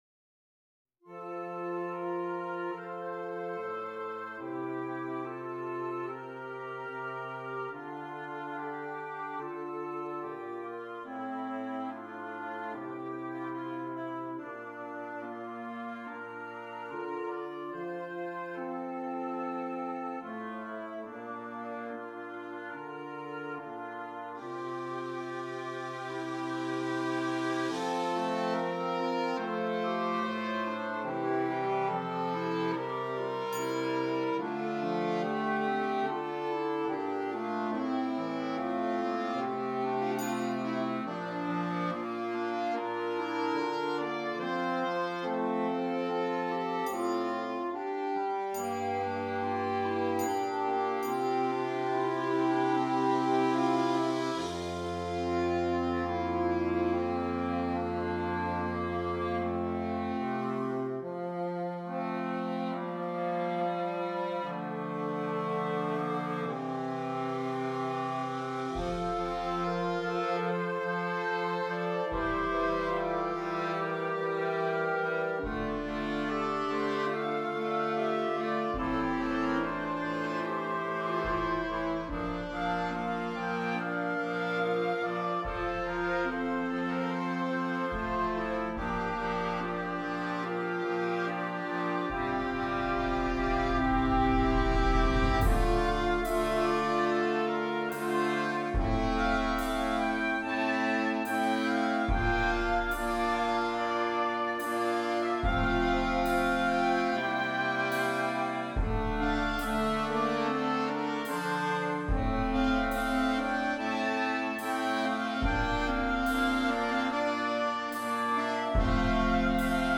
Interchangeable Woodwind Ensemble
calm, reflective piece